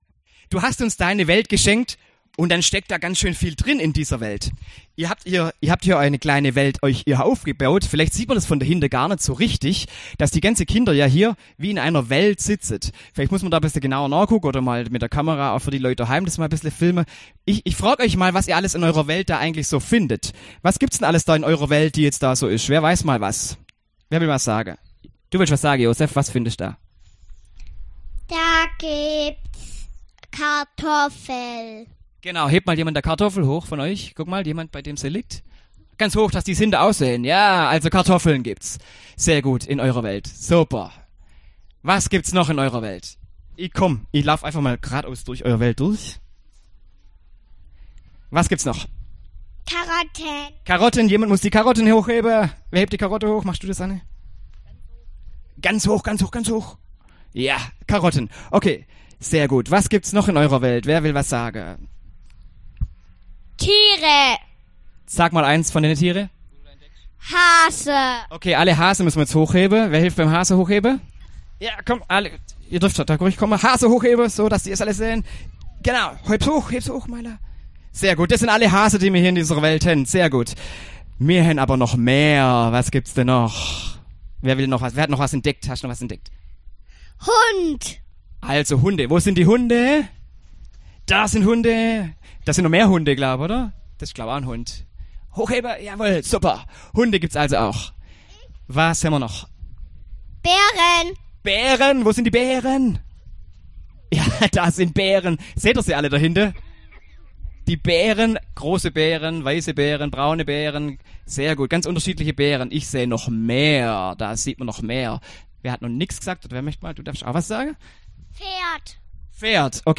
Predigt
im Gottesdienst im Grünen mit Kindergarten und Erntebitte